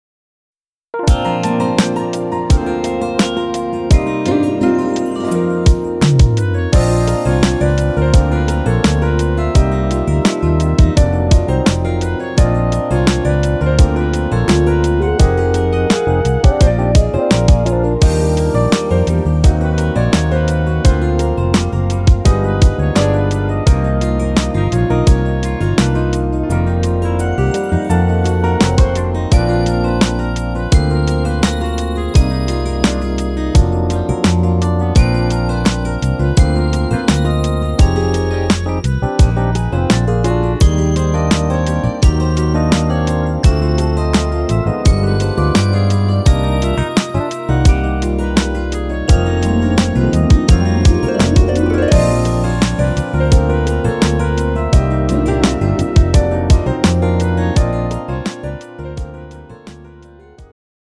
음정 남자-1키
장르 축가 구분 Pro MR